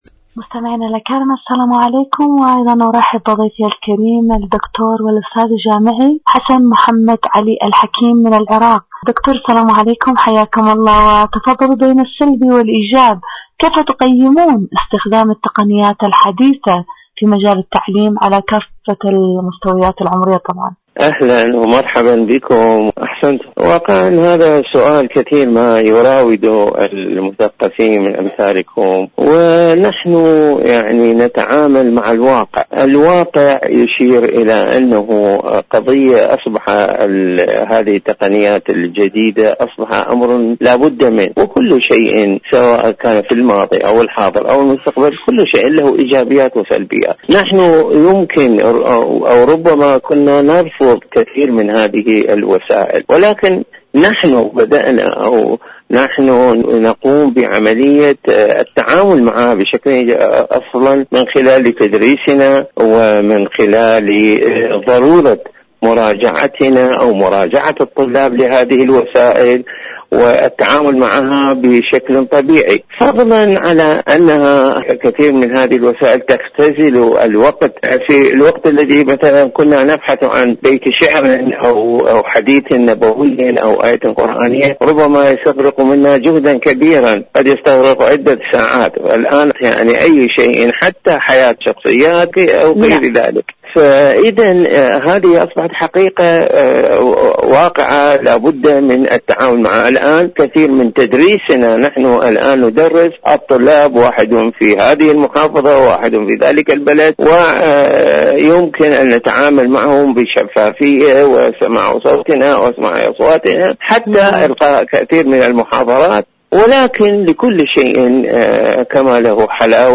إذاعة طهران-معكم على الهواء: مقابلة إذاعية